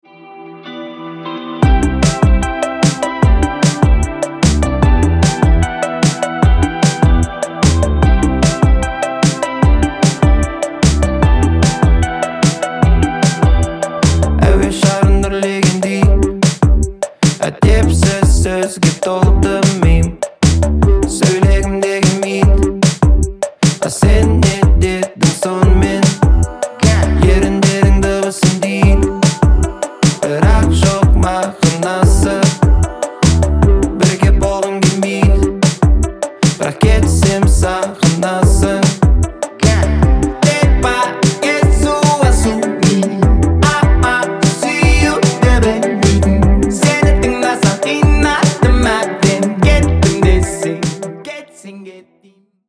• Качество: 320, Stereo
поп
мужской вокал
Q-Pop